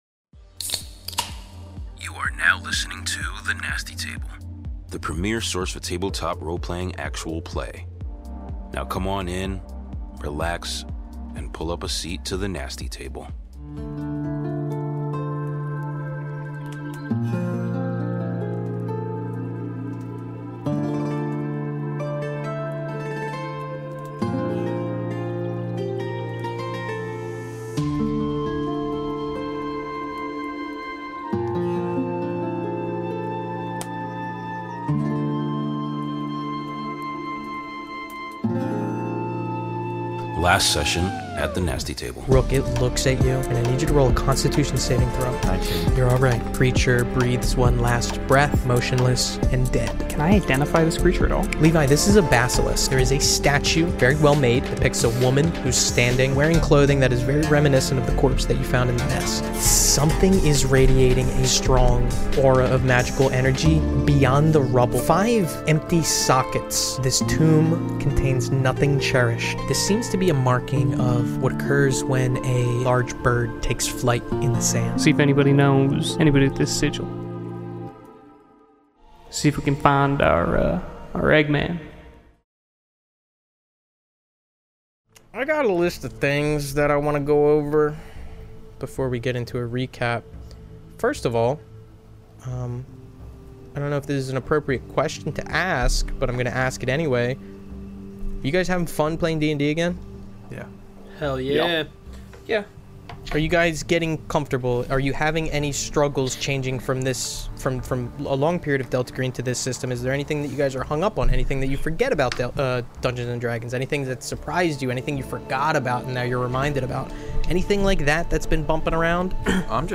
Join The Nasty Table, where five friends and longtime players of Table Top Role-Playing games play together in various campaigns across different systems and settings (Delta Green, Dungeons & Dragons, Call of Cthulhu, StarFinder and more).
Tune in for new TTRPG actual play episodes every Tuesday!